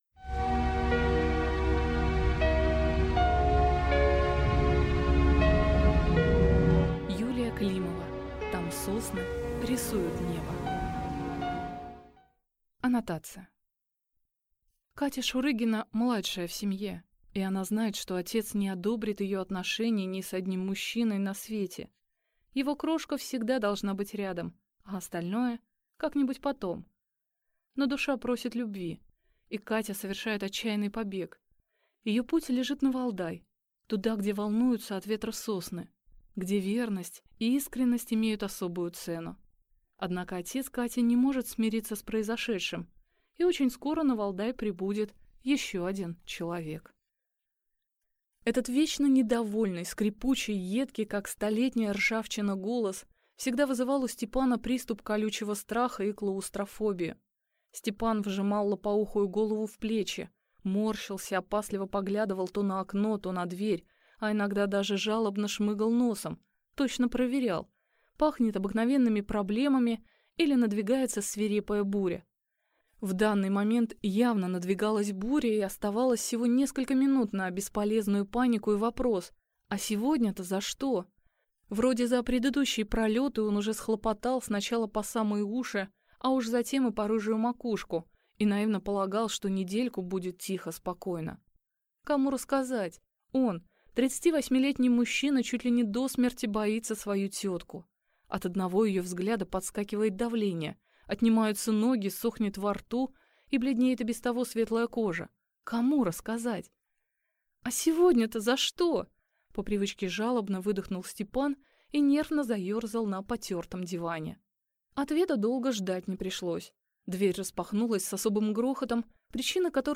Аудиокнига Там сосны рисуют небо | Библиотека аудиокниг